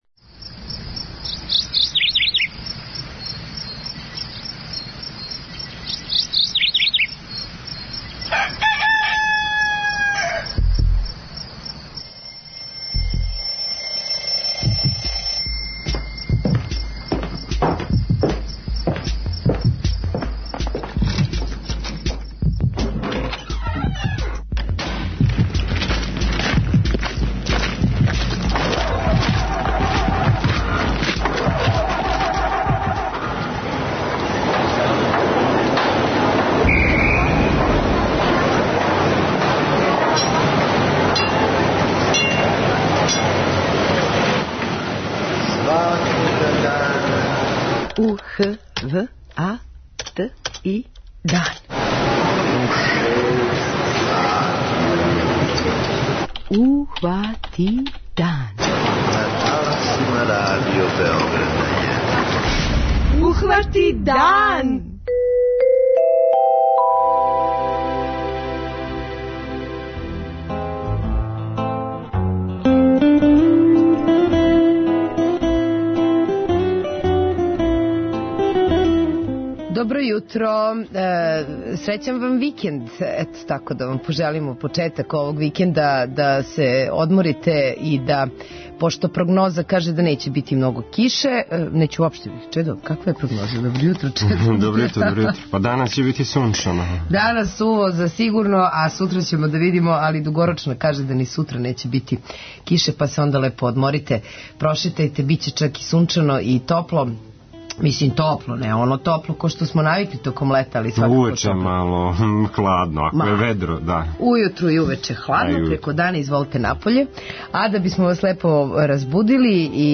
преузми : 32.36 MB Ухвати дан Autor: Група аутора Јутарњи програм Радио Београда 1!